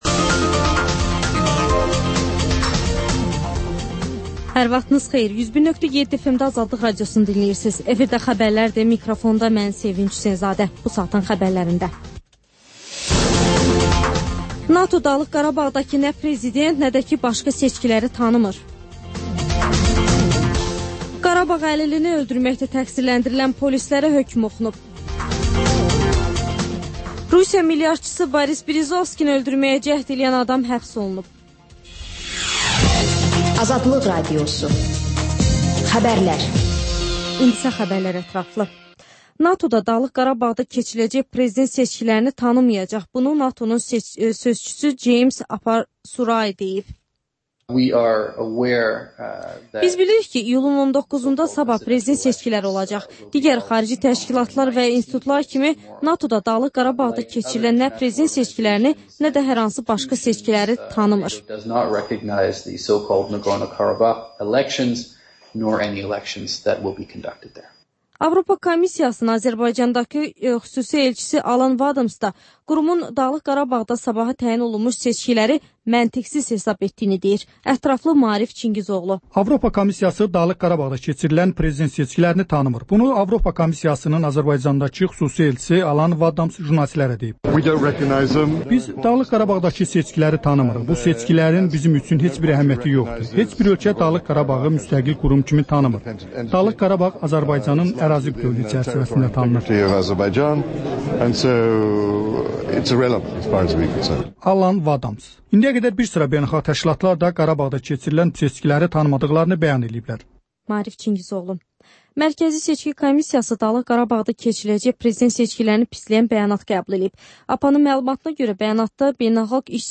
Xəbərlər, müsahibələr, hadisələrin müzakirəsi, təhlillər, sonda 14-24: Gənclər üçün xüsusi veriliş